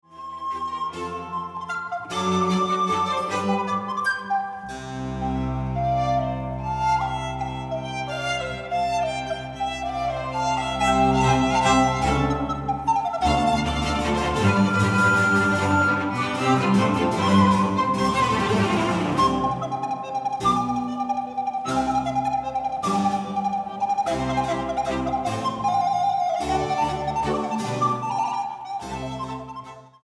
para flauta, cuerdas y continuo